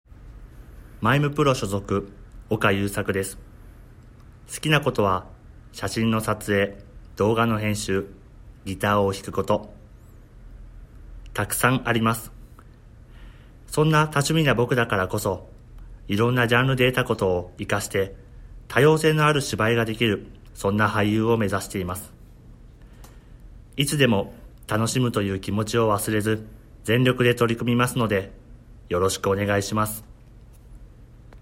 出⾝地・⽅⾔ 兵庫県・関西弁
ボイスサンプル
自己紹介